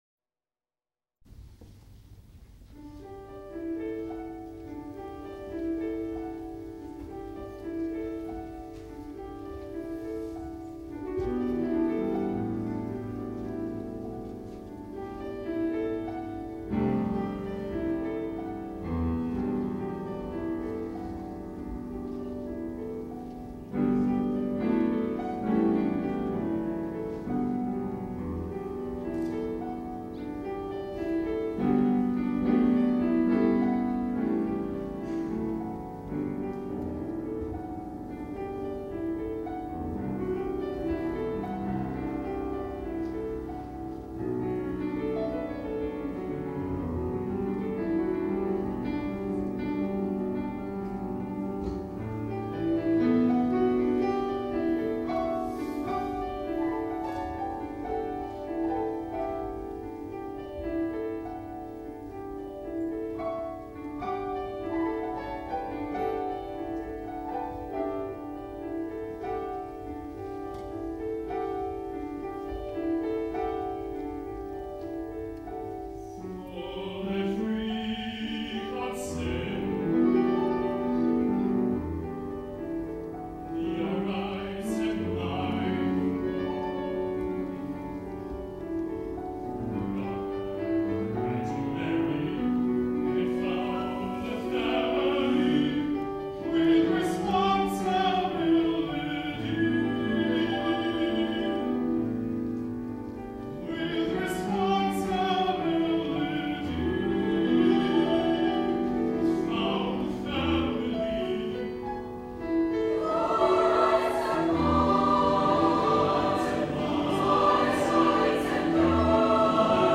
baritone, choir, piano